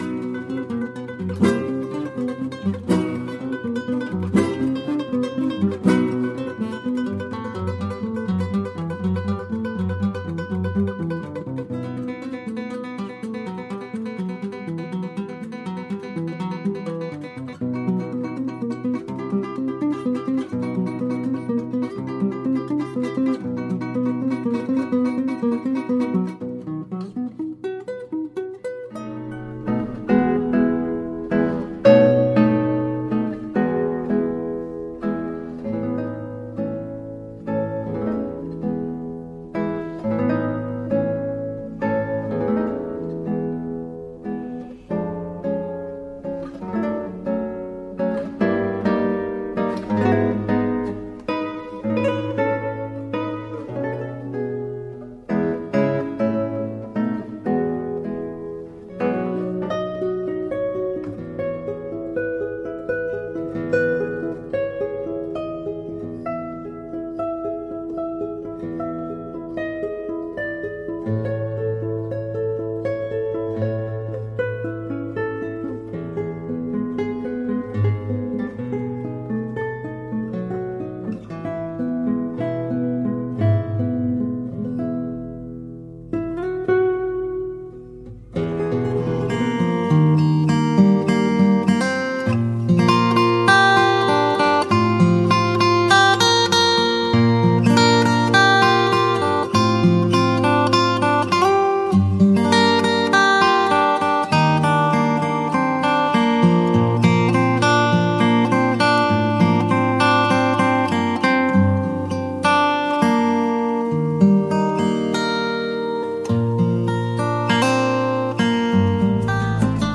Slow Instrumental Guitar
Slow Tempo Instrumental Acoustic and Classical Guitar Tracks